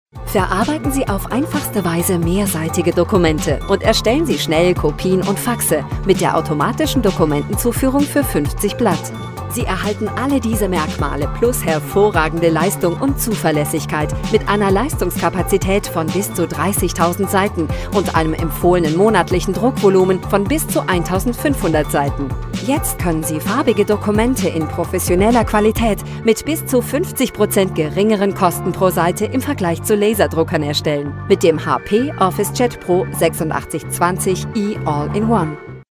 Ausgebildete Sprecherin mit eigenem Studio!
Sprechprobe: Industrie (Muttersprache):